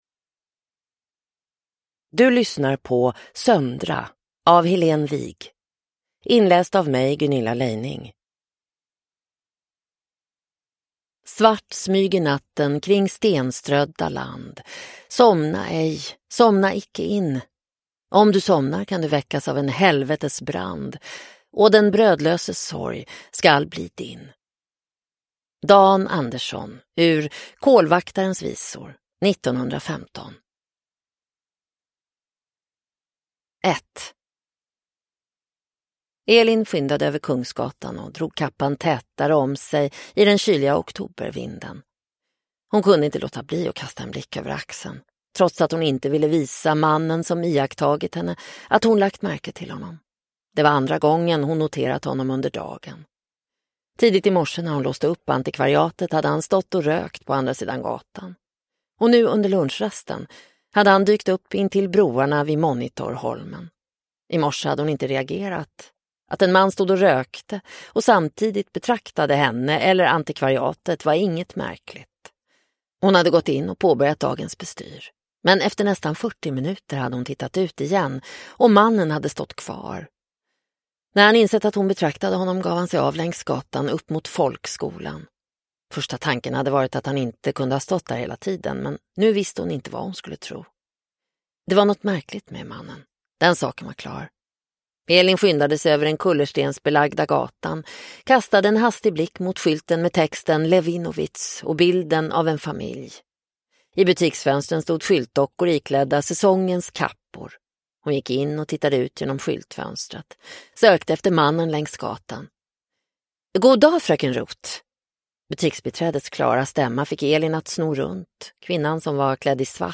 Söndra (ljudbok) av Helén Wigh